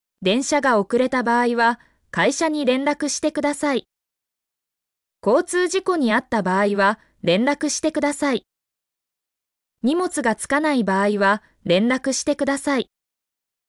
mp3-output-ttsfreedotcom-11_2UPfk6sD.mp3